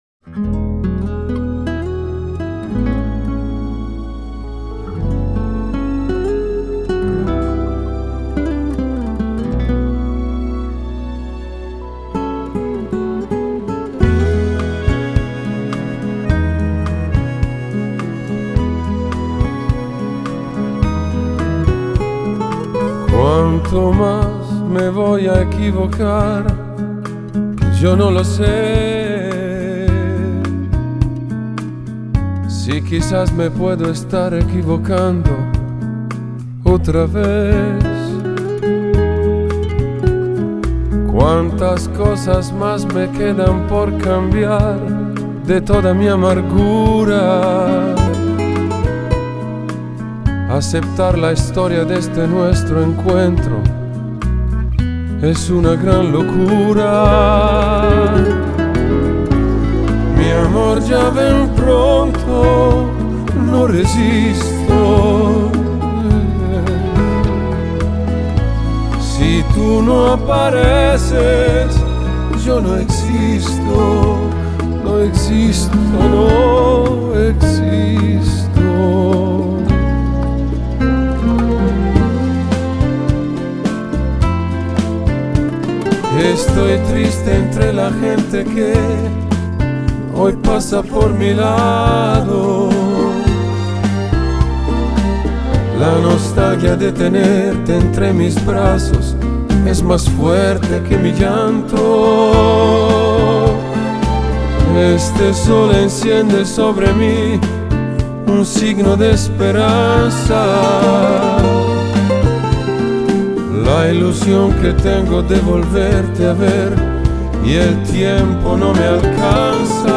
Genre: Vocal, Classical, Crossover